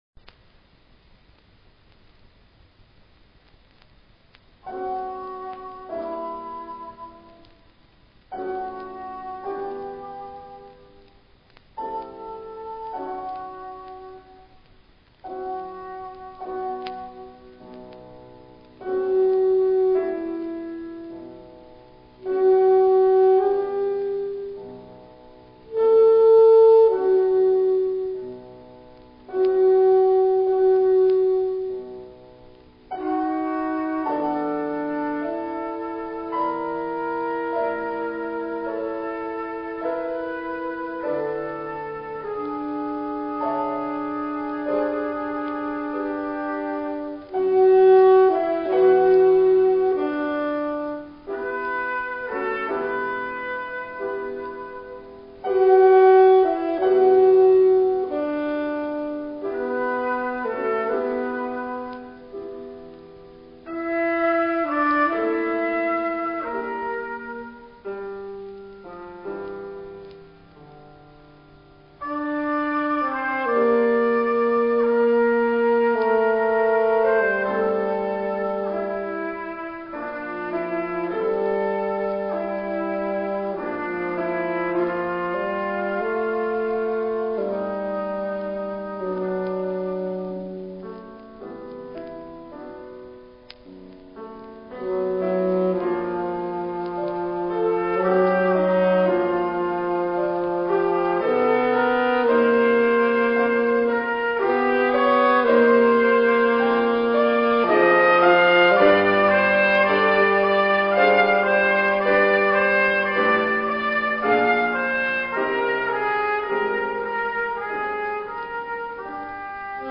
concerto pour saxophone trompette et piano